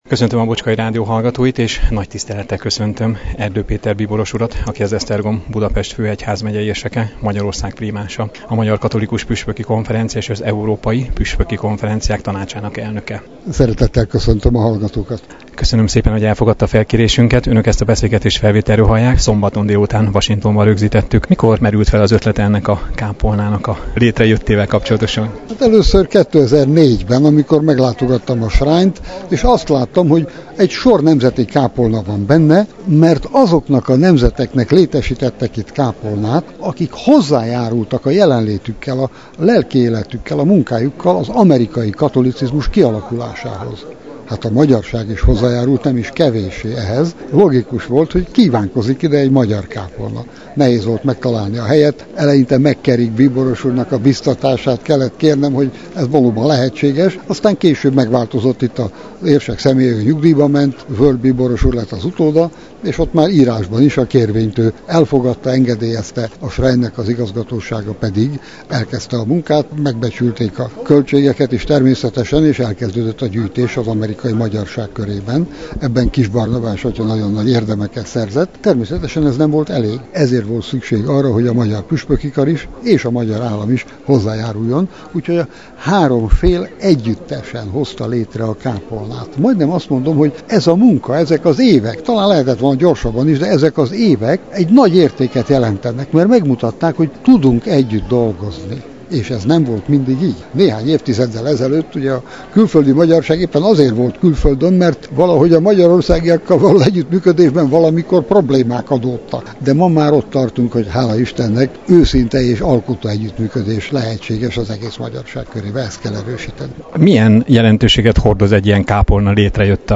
Erdo-Peter-interju.mp3